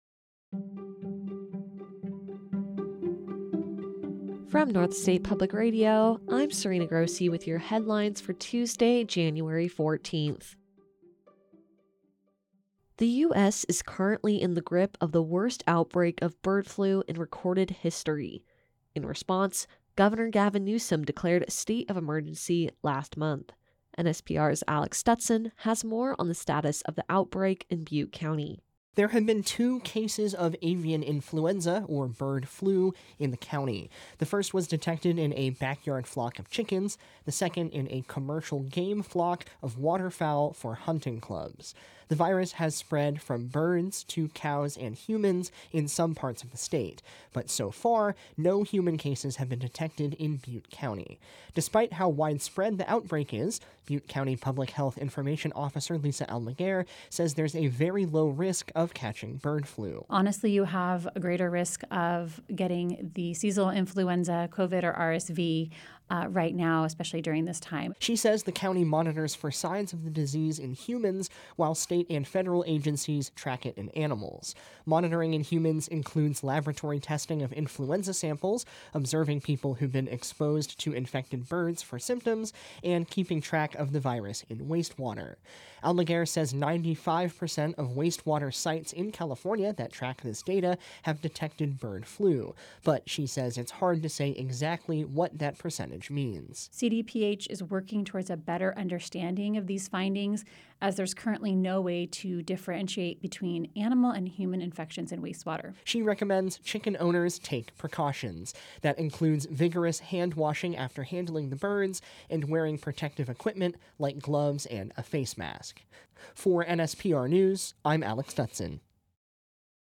A daily podcast from NSPR featuring the news of the day from the North State and California in less than 10 minutes. Hosted by NSPR Staff, and available at 8:30 a.m. every weekday.